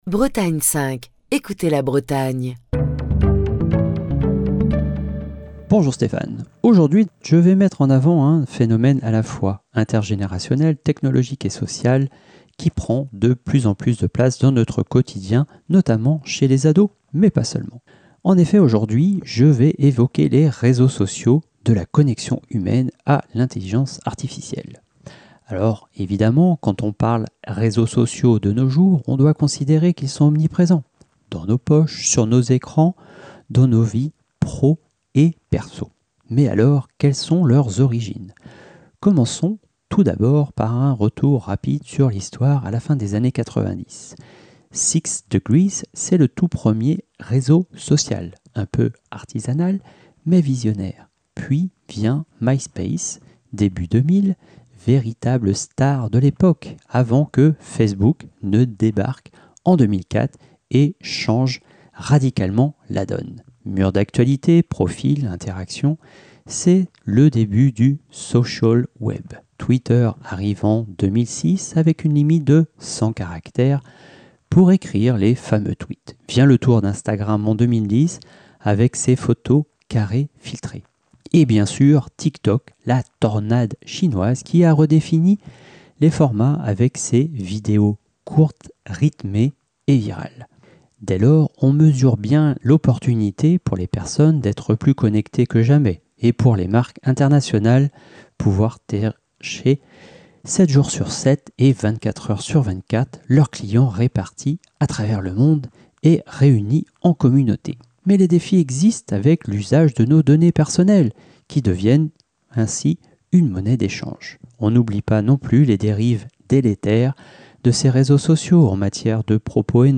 Chronique du 4 juin 2025.